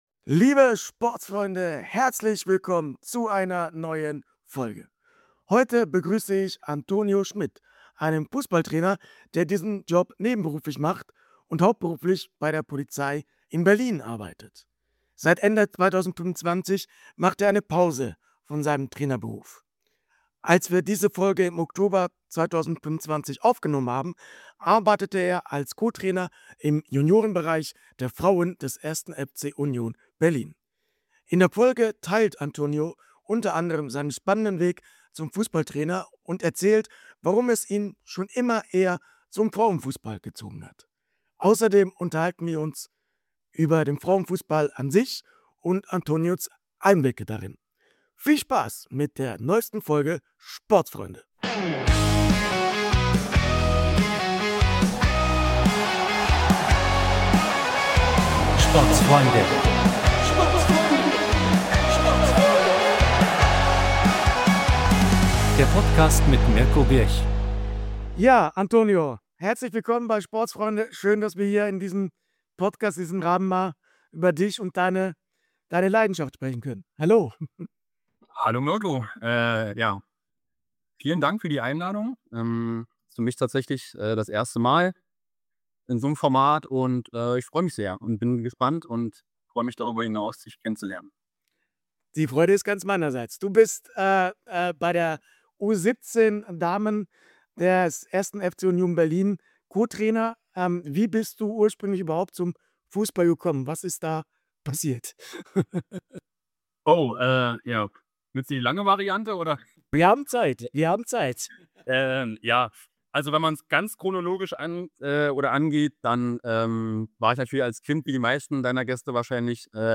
Wir haben dieses Gespräch im Oktober 2025 aufgenommen